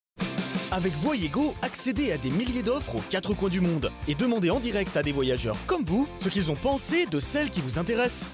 中低音域の声で、暖かさと親しみやすさを兼ね備えています。
明るい(フランス語)